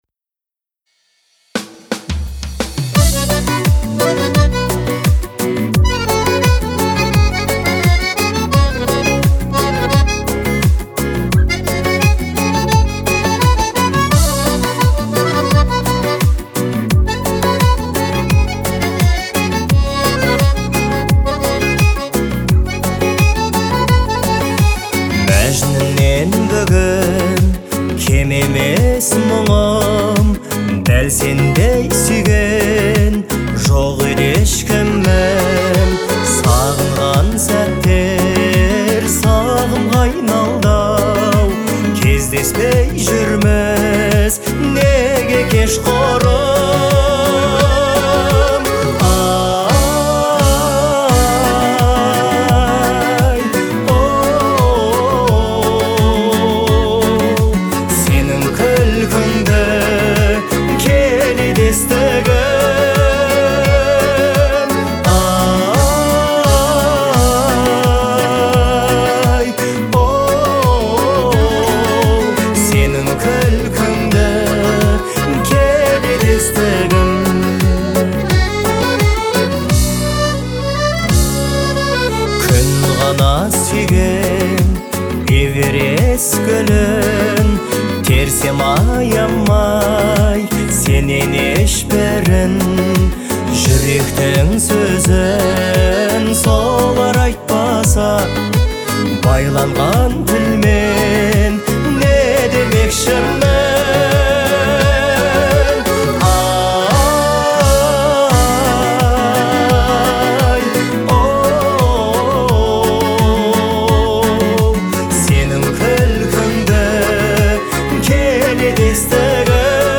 это нежная и мелодичная песня в жанре поп